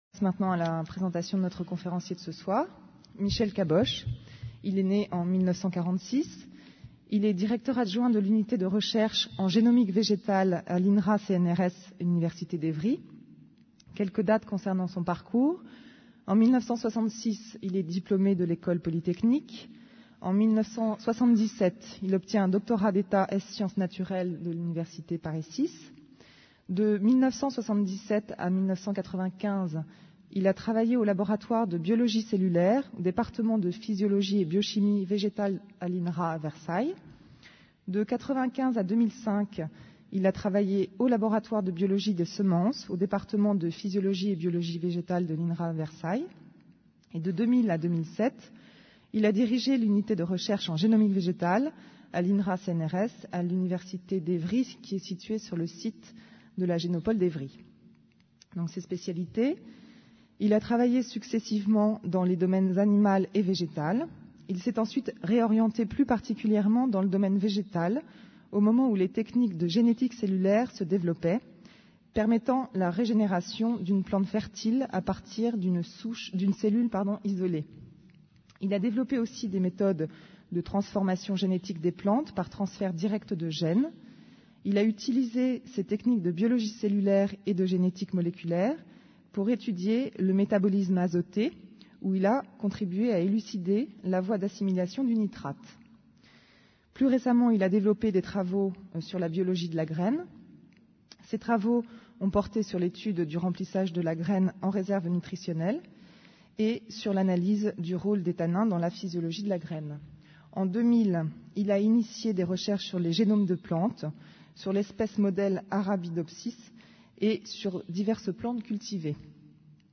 Une conférence du cycle : Qu'est ce que la vie ? Où en est la connaissance du génome ?